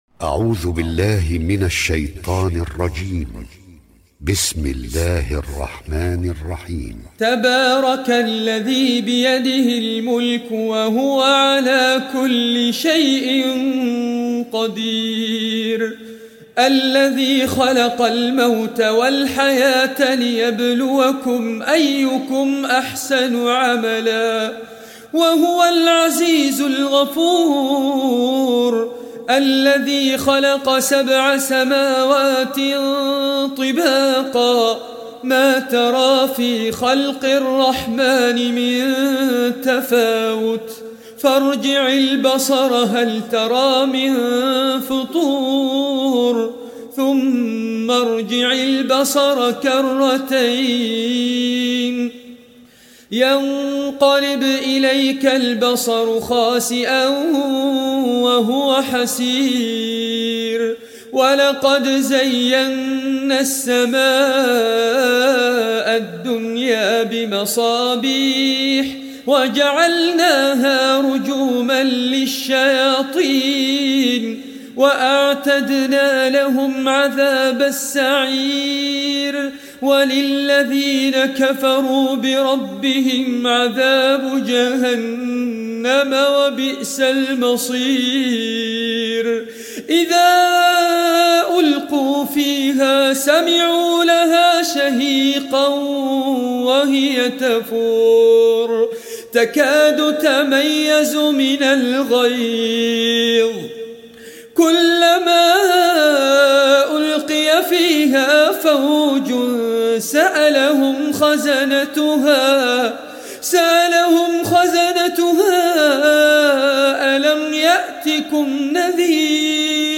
Surah Al-Mulk Recitation by Sheikh Fares Abbad
Listen online and download very rare and beautiful recitation of Surah al Mulk.